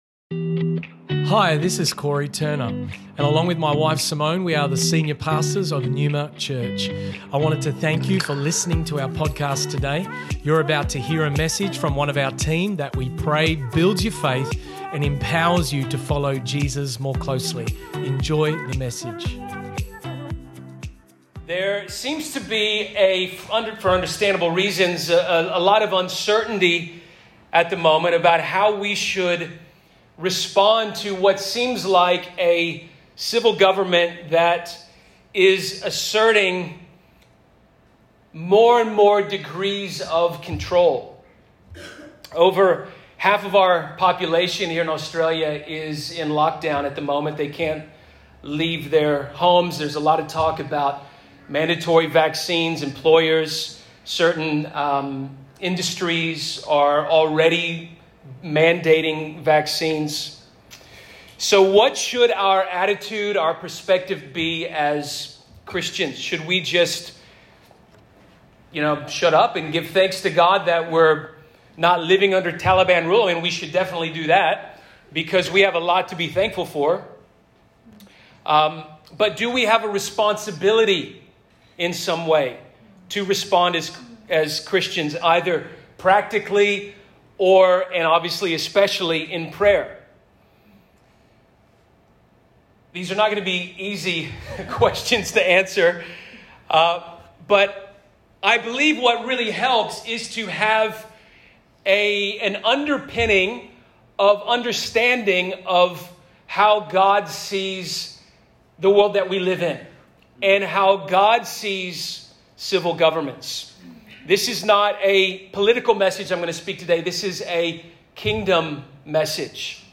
This message was originally preached on 29 August 2021 at NEUMA Church Perth.